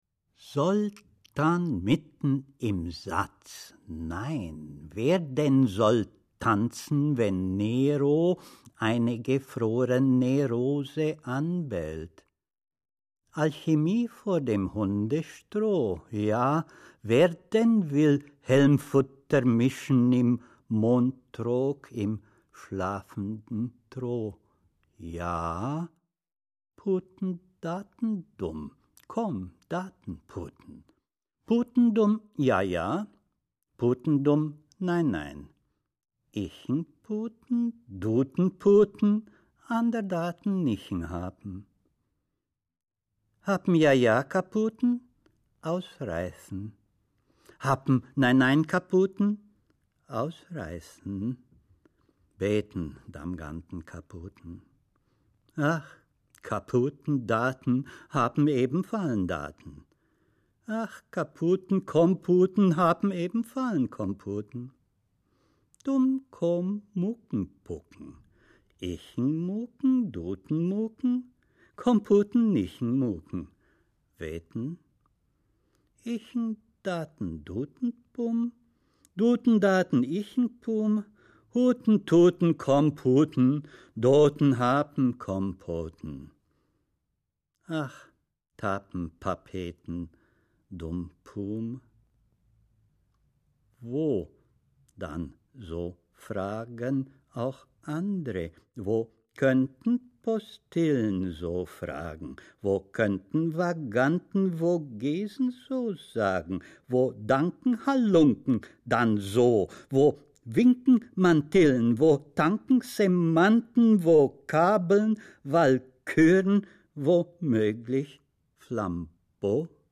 Über dieses Buch: Oskar Pastior liest Gedichte aus 30 Jahren: diese CD, zuerst 1997 veröffentlicht auf dem Musiklabel der Berliner Akademie der Künste und erschienen zu Oskar Pastiors 70. Geburtstag, bringt einen Querschnitt durch Pastiors poetisches Werk.